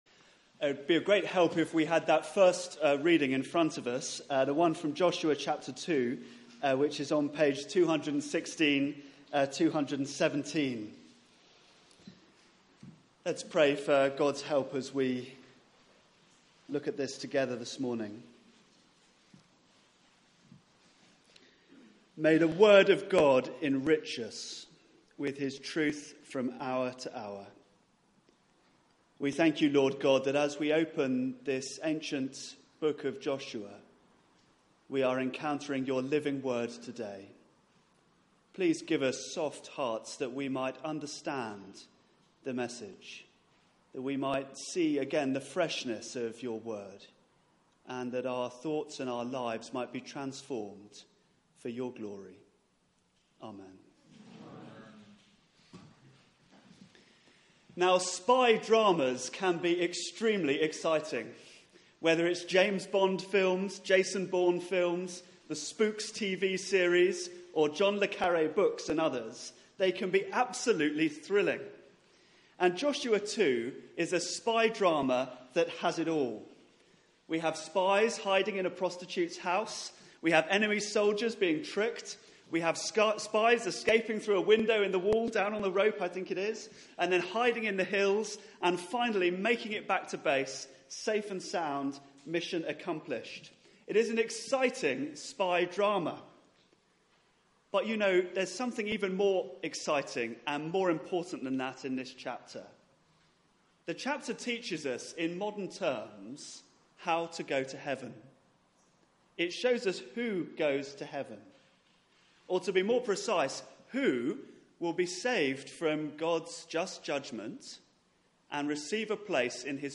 Theme: The Kindness of God Sermon